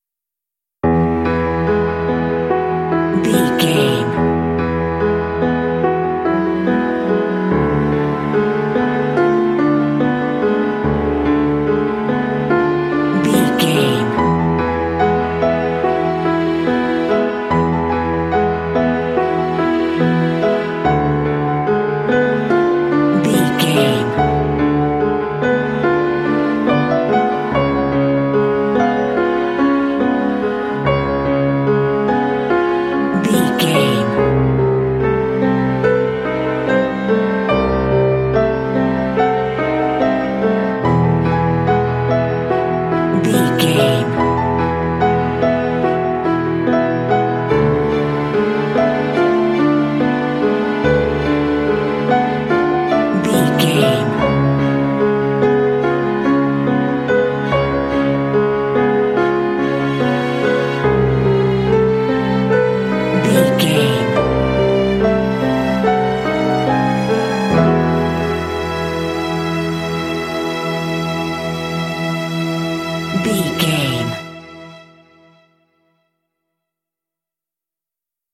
Ionian/Major
sentimental
dreamy
strings
cinematic
film score